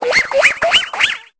Cri d'Otarlette dans Pokémon Épée et Bouclier.